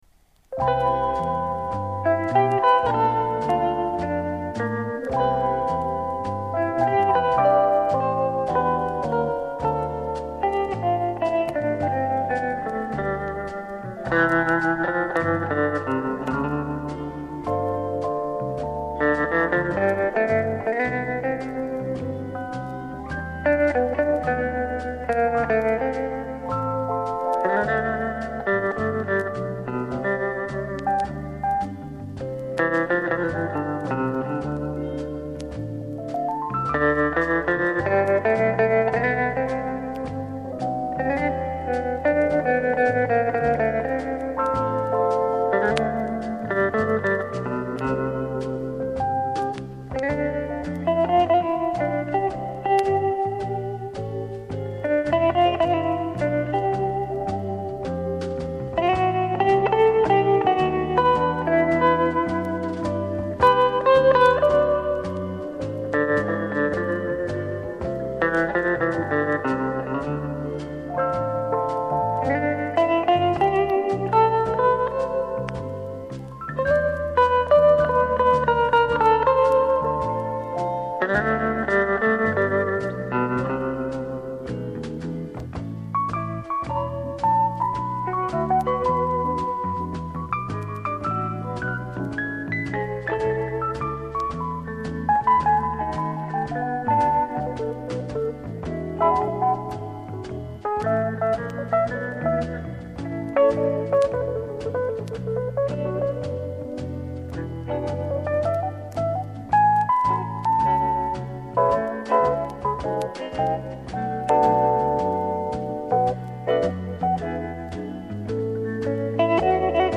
Известный гитарист.